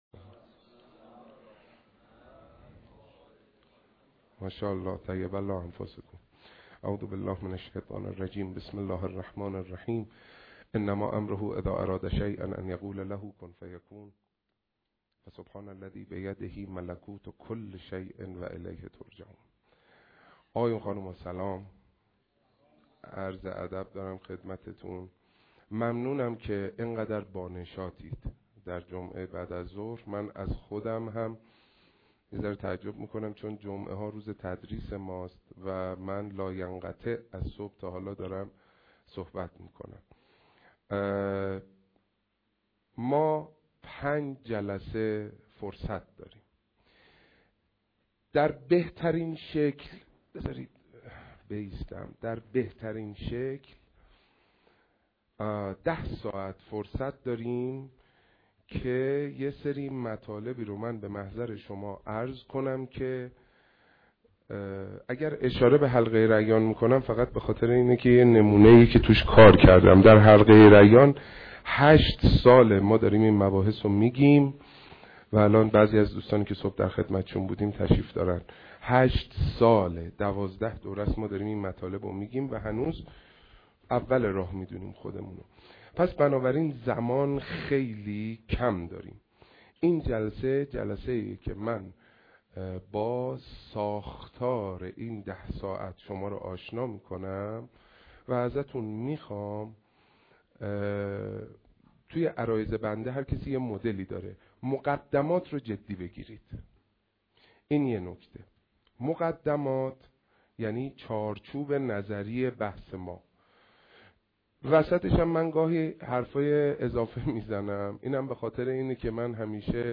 اولین جلسه شعر و تصنیف آیینی دم قرار
با توجه به اهمیت نوحه و شعر خبرگزاری تسنیم اولین دوره کارگاه شعر و تصنیف آیینی را در باشگاه خبرنگاران پویا برگزار کرد.